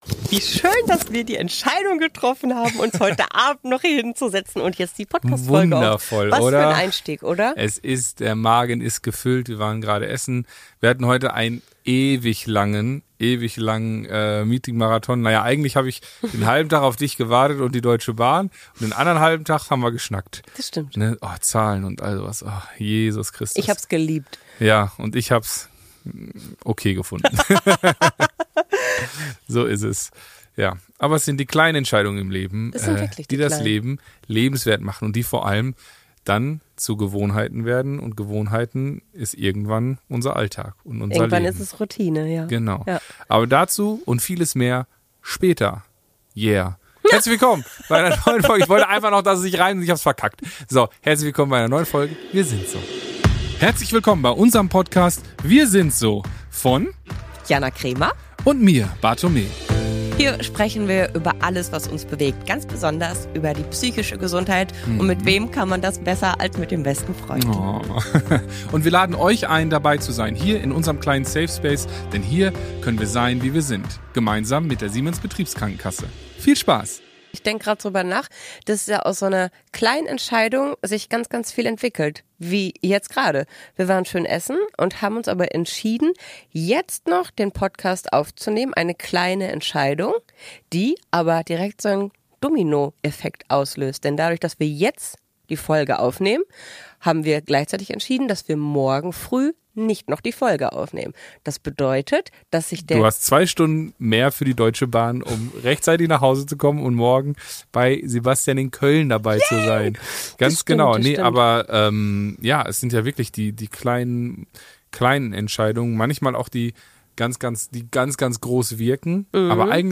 Interview
42:45 Play Pause 20d ago 42:45 Play Pause Nghe Sau Nghe Sau Danh sách Thích Đã thích 42:45 Dieses Mal ohne Video, weil ich auf dem Schmerzkongress nur mit dem Audio-Equipment unterwegs gewesen bin.